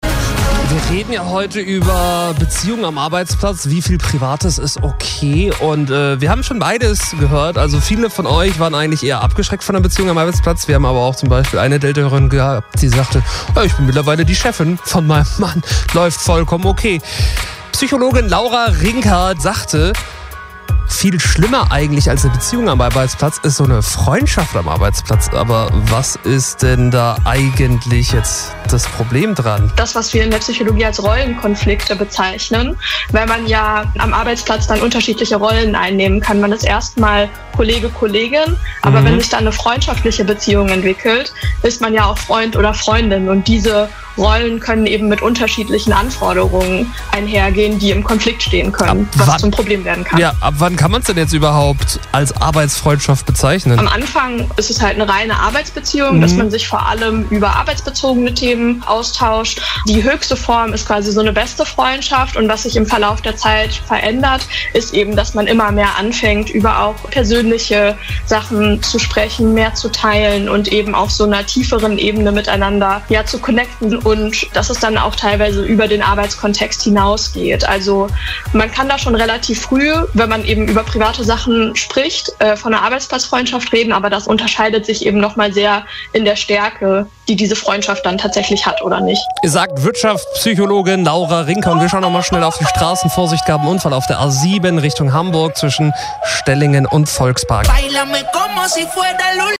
delta radio Interview „Rollenkonflikte: Wenn Freundschaft im Job zur Gratwanderung wird“, April 2, 2025 [